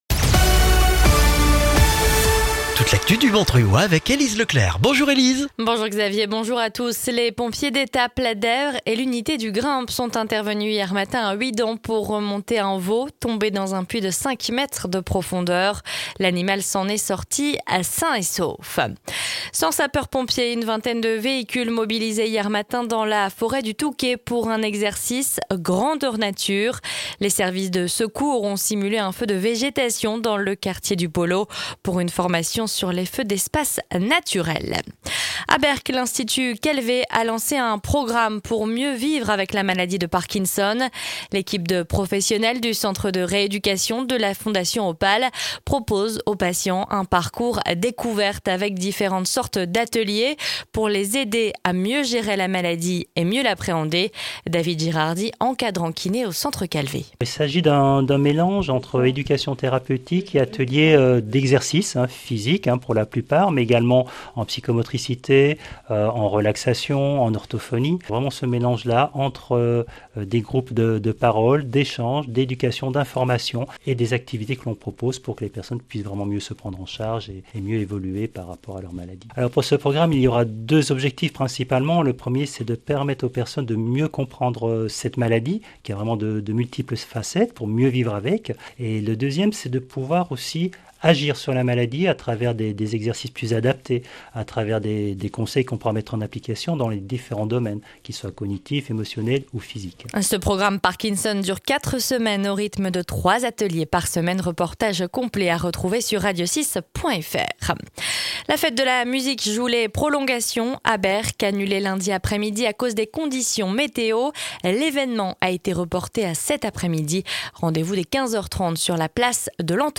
Le journal du vendredi 25 juin dans le Montreuillois